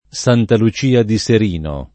Santa Lucia [S#nta lu©&a] top. — es.: Santa Lucia del Mela [S#nta lu©&a del m$la] (Sic.); Santa Lucia di Serino [